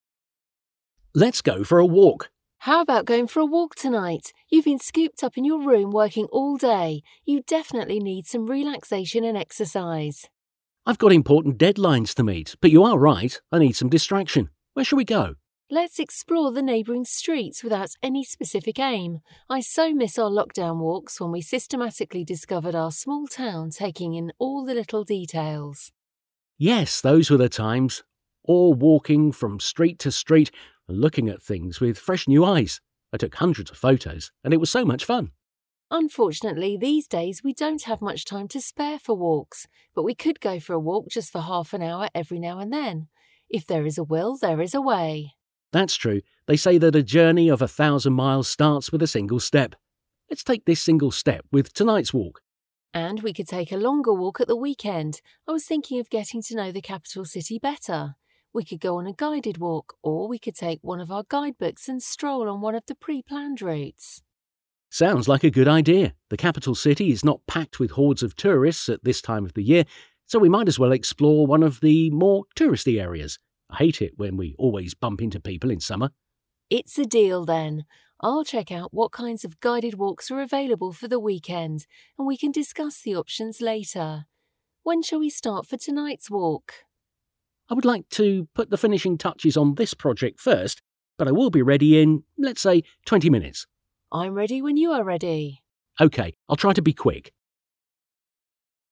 A párbeszédet a magazin 80. oldalán olvashatod.